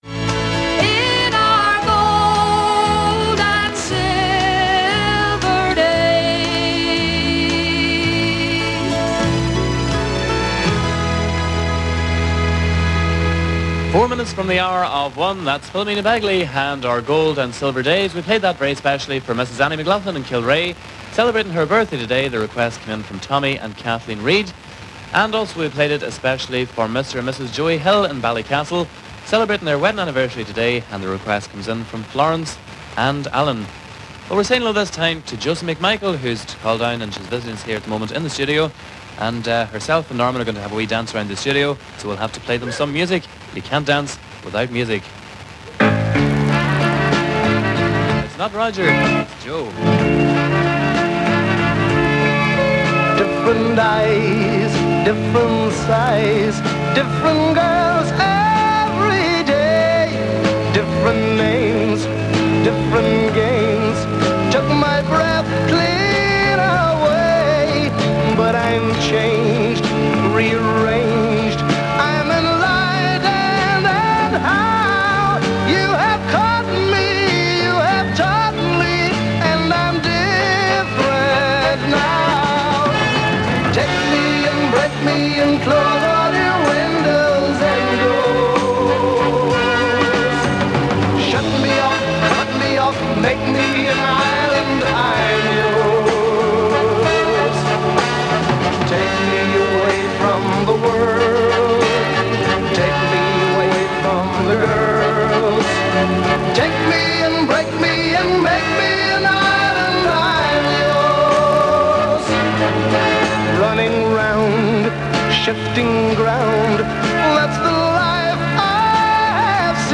This recording of Donegal pirate Radio North was made in the summer of 1997 and shows how the station continued to generate significant advertising revenue many years after the enactment of the new broadcasting laws.
The music is a mixture of country and Irish, oldies and pop or chart.
There is plenty of fading as it was recorded some distance from the transmitter.